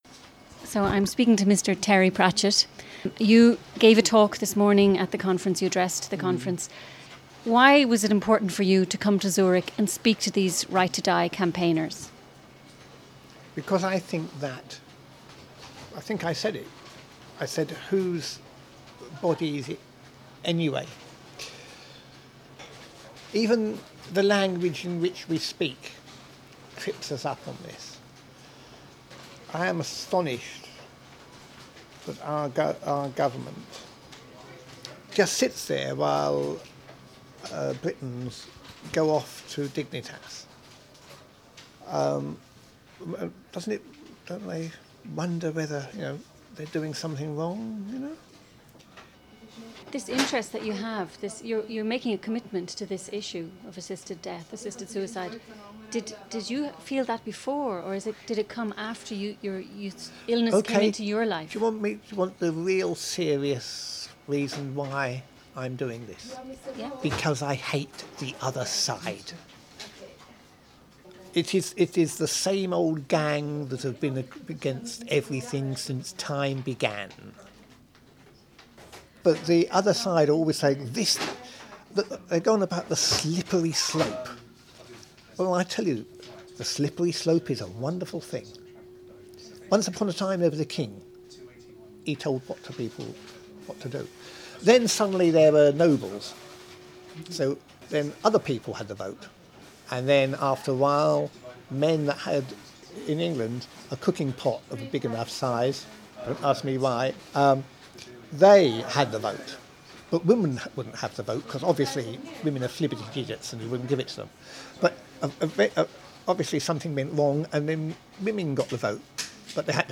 Terry Pratchett interview